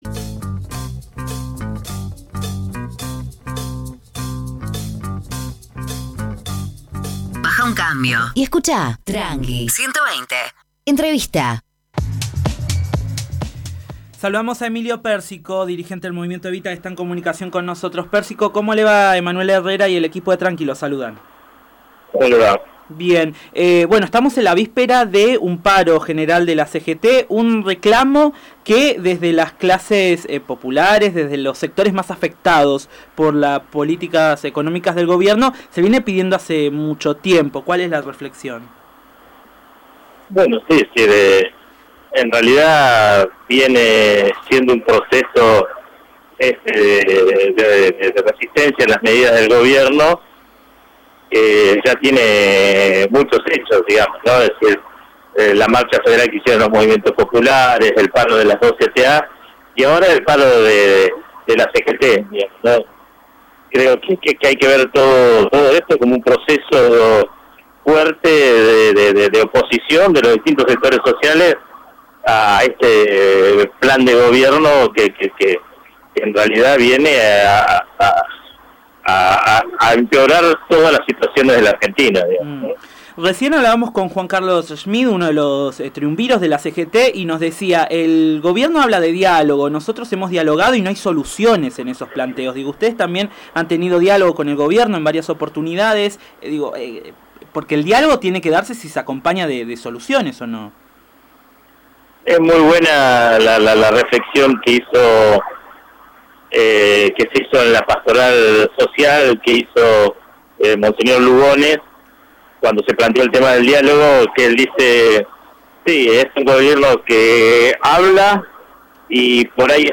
(23.06.18) El dirigente social y secretario general del Movimiento Evita, Emilio Pérsico, conversó con el programa Tranqui 120 sobre la situación actual del país.